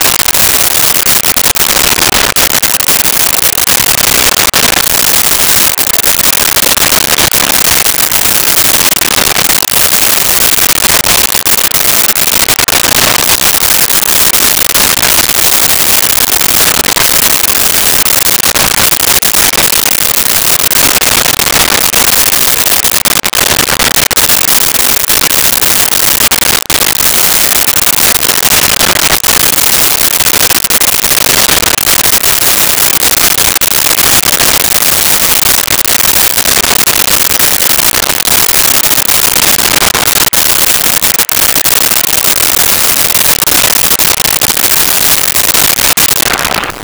Rowboat Movement.wav